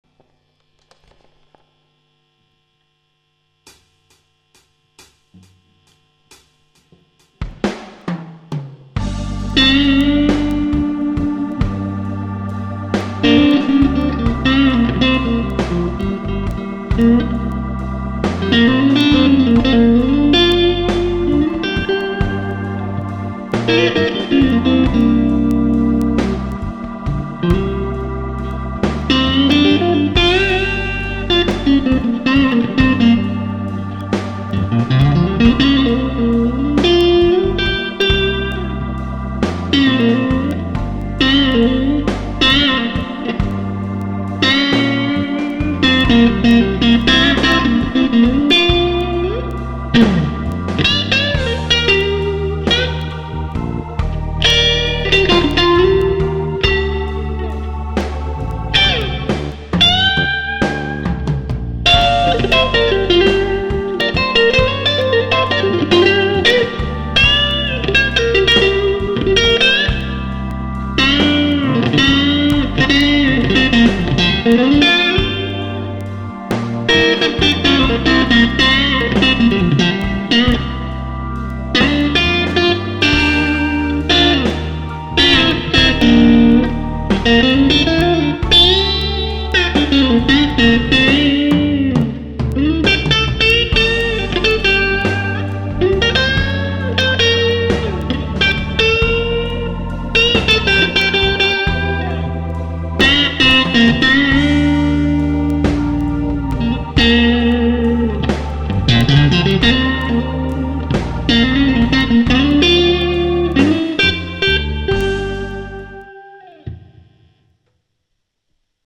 Fat responsive clean to semi clean with a biting yet smooth overdrive and a lot of sustain.
>> Clean tones
Clean_GT.MP3